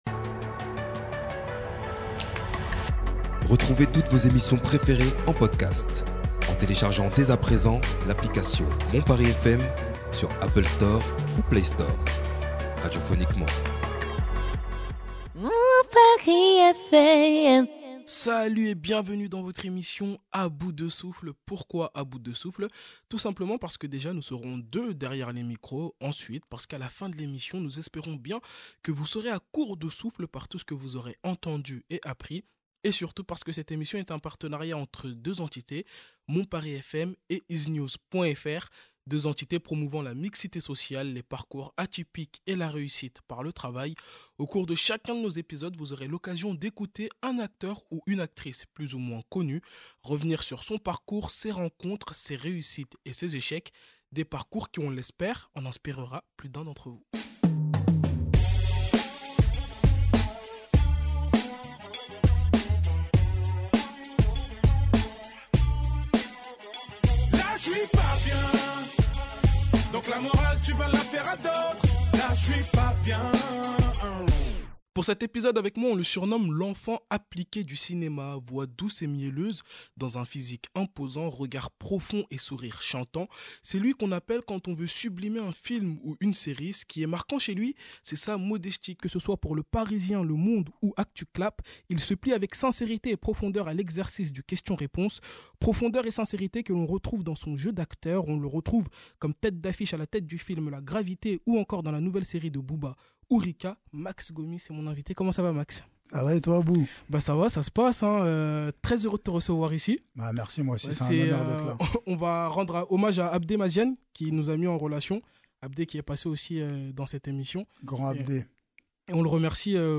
voix douce et mielleuse dans un physique imposant, regard profond et sourire chantant. C’est lui qu’on appelle quand on veut sublimer un film ou une série.